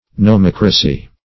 Search Result for " nomocracy" : The Collaborative International Dictionary of English v.0.48: Nomocracy \No*moc"ra*cy\, n. [Gr. no`mos law + -cracy, as in democracy.]